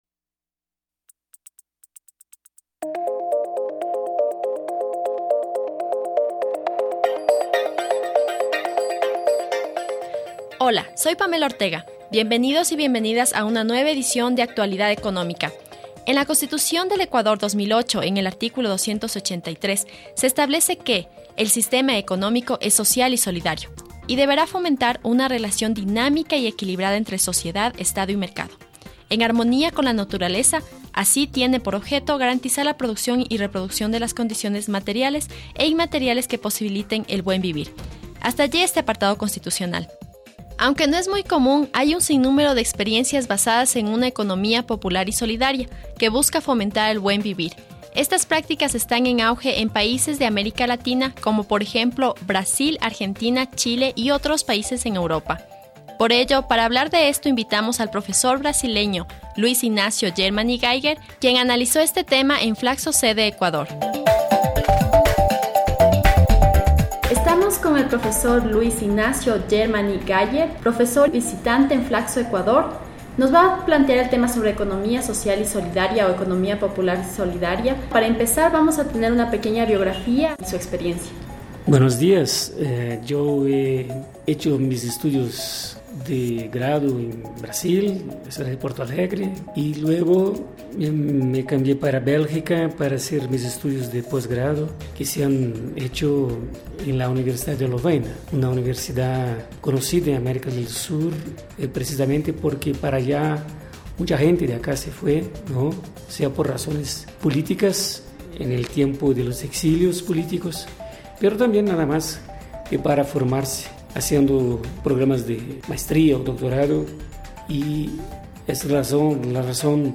Recogemos la experiencia de un investigador y docente brasileño, quien sostiene que existen diferentes maneras de concebir las relaciones sociales más allá del mercado, con el objetivo de dar privilegio al ser humano y a sus necesidades.